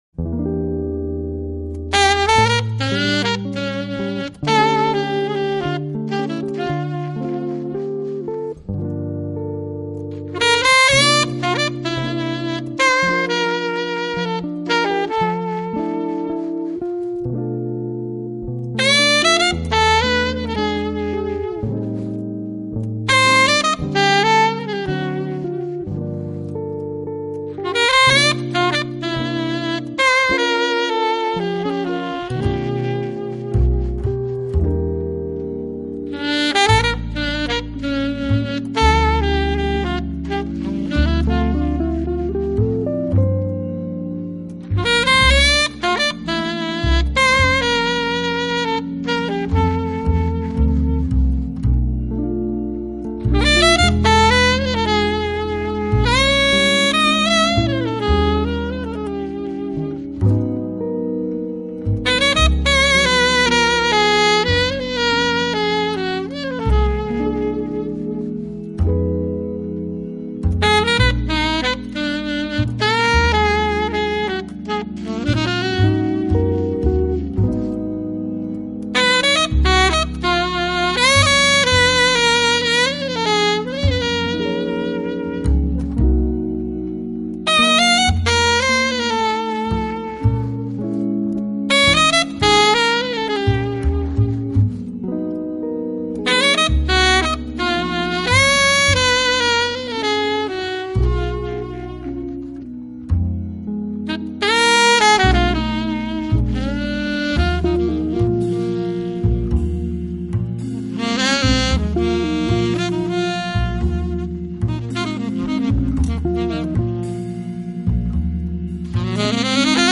【爵士萨克斯】
专辑类型：Jazz